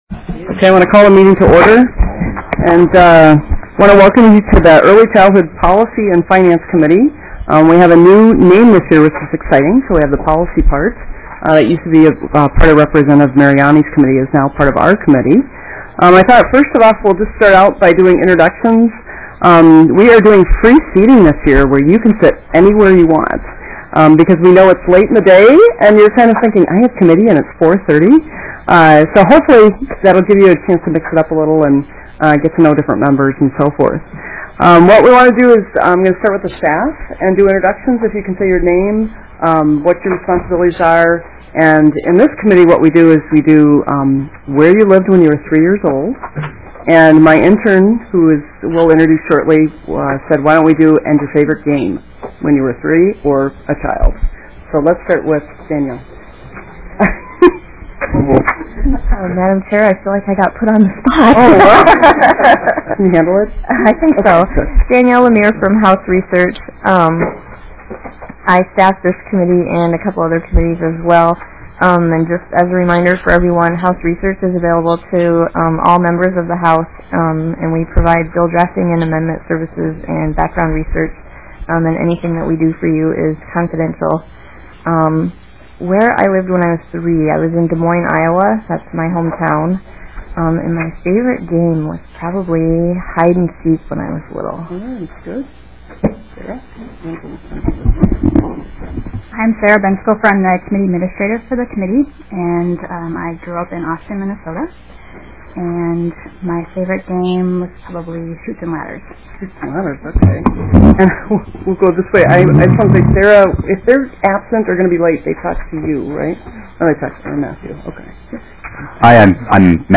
Representative Nora Slawik, Chair of the Early Childhood Finance & Policy Division, called the meeting to order at 4:35 pm on Tuesday, January 13th 2009, in Room 200 of the State Office Building.